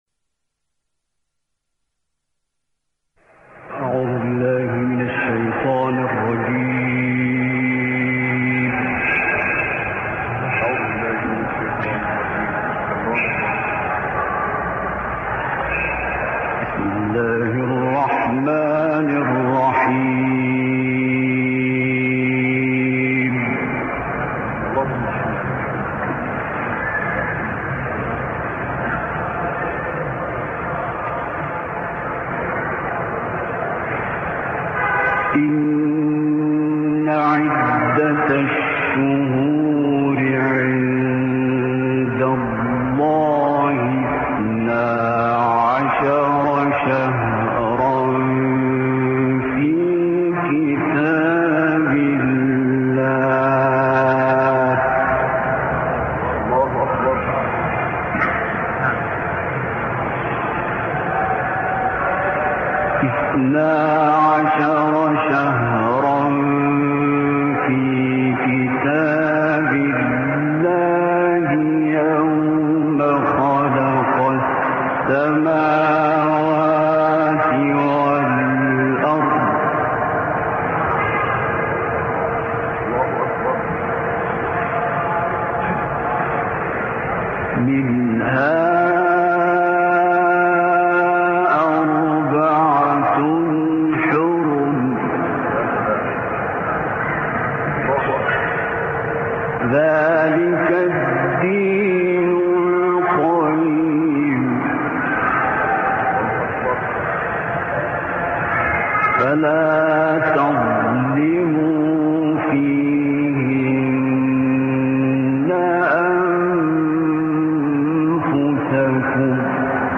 تلاوت «عبدالباسط» در مسجد امام حسین(ع)
گروه شبکه اجتماعی: تلاوت آیاتی از سوره مبارکه توبه، با صدای عبدالباسط محمد عبدالصمد که در مسجد امام حسین(ع) اجرا شده است، ارائه می‌شود.